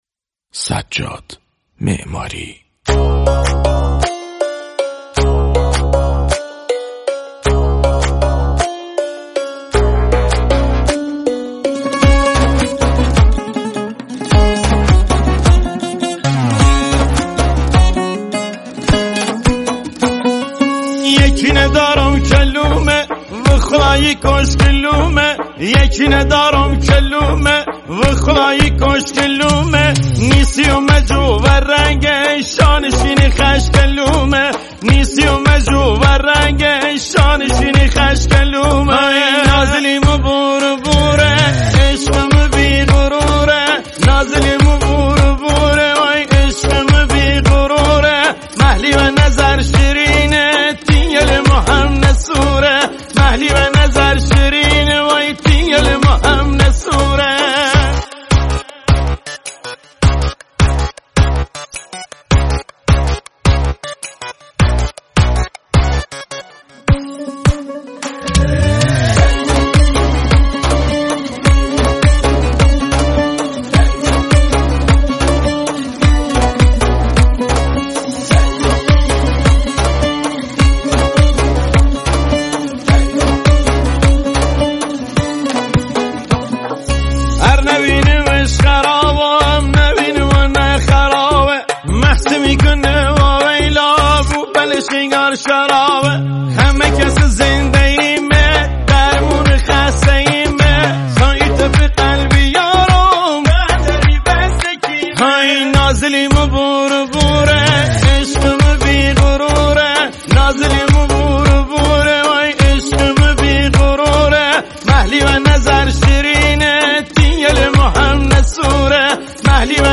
خواننده آهنگ